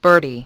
1 channel
birdie.mp3